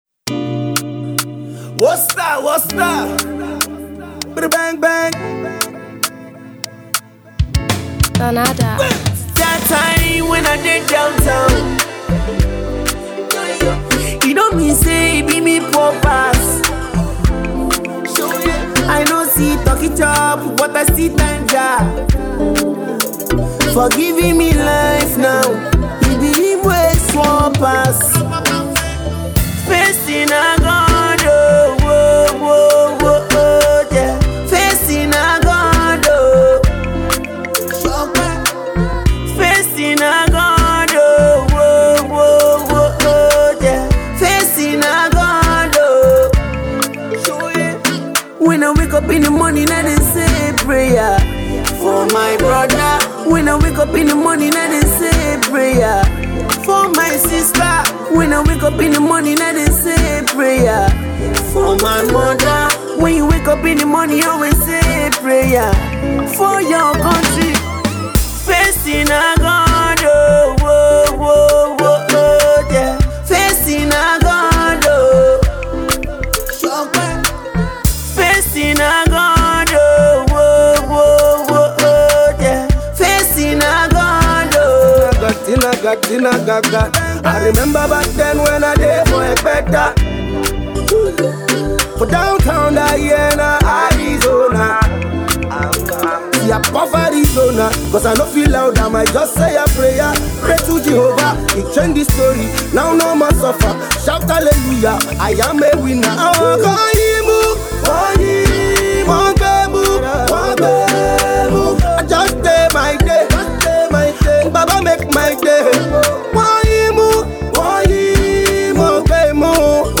Nigerian Music
sultry verse